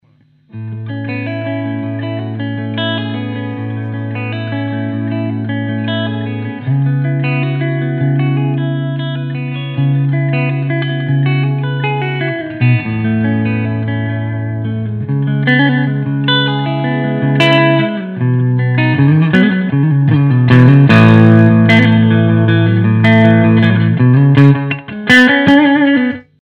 To ensure the amp’s true voice shines through, recordings were made using a Shure SM57 mic on a Marshall cabinet, alongside a direct line from the amp’s balanced SM57 mic sim output.
We kept effects minimal to let the amp speak for itself — just a hint of reverb, light delay, and a short wah pedal section.”
The-Sabre-Clean-demo-6.mp3